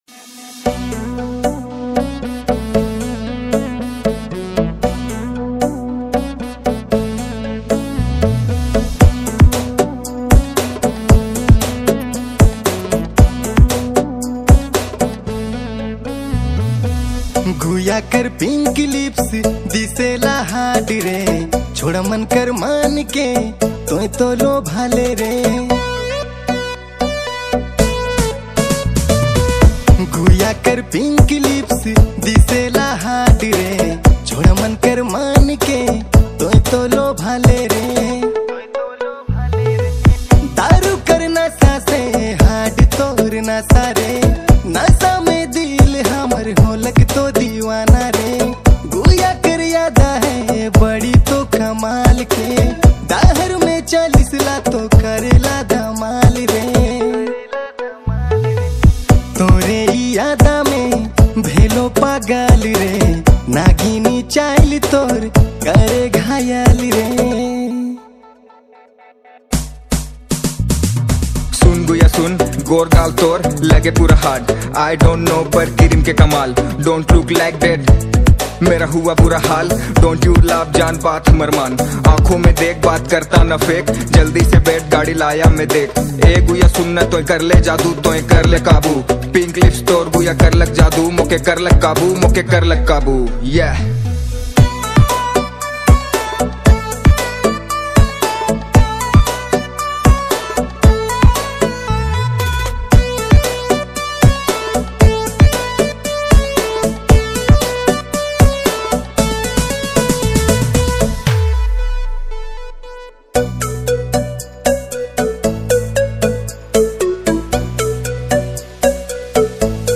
New Nagpuri Dj Songs Mp3 2025